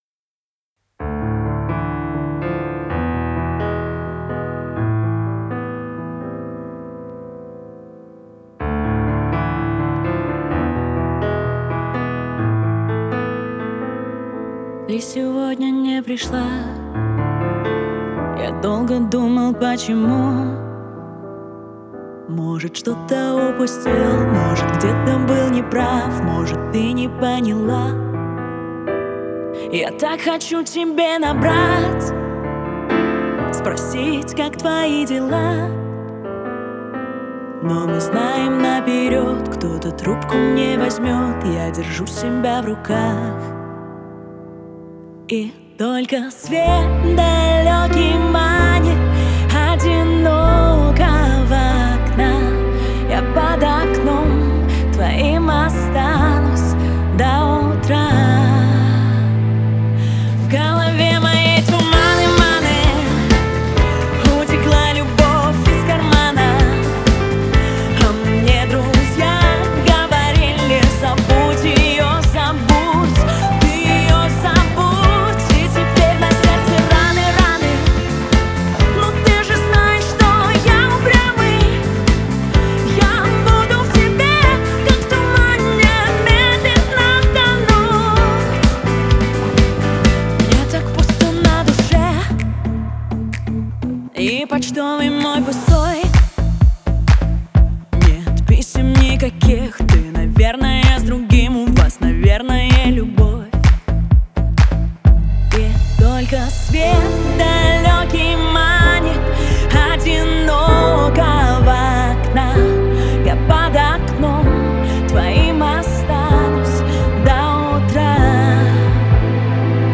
барабаны, бас, клавиши, мужской и женский вокал